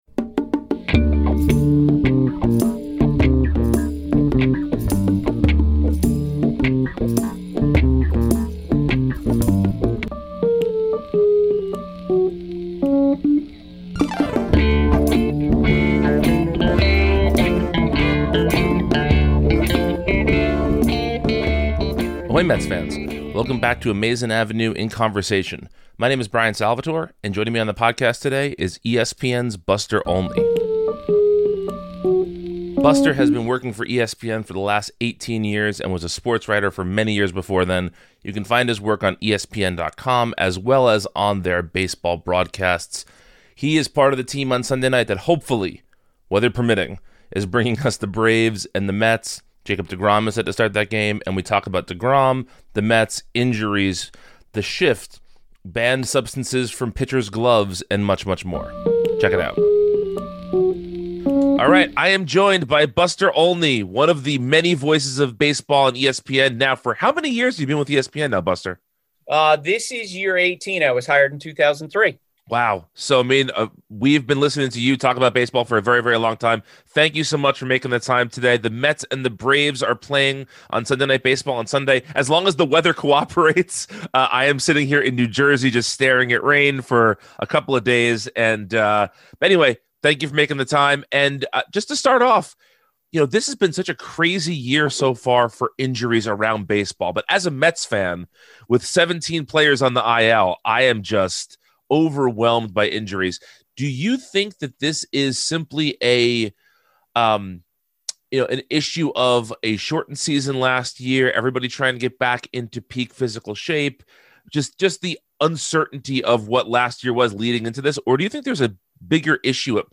Welcome to Amazin’ Avenue in Conversation, a podcast from Amazin’ Avenue where we invite interesting people on the show to talk about themselves, the Mets, and more.